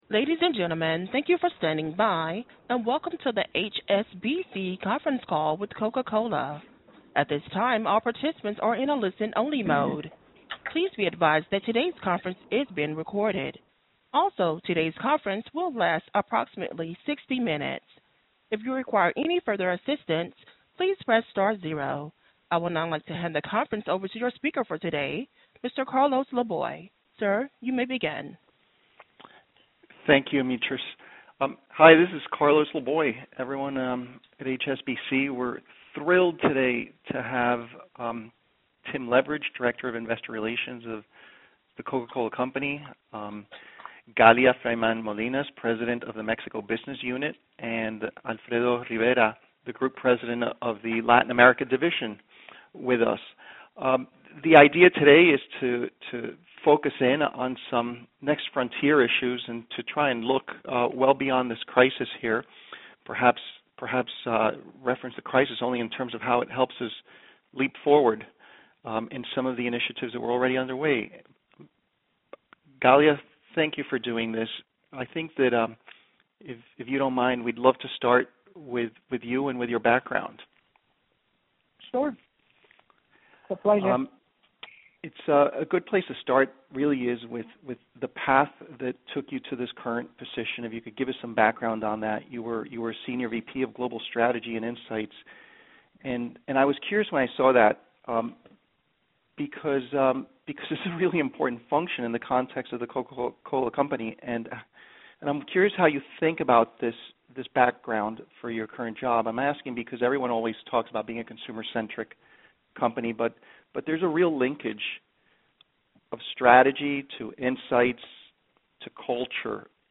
HSBC Conference Call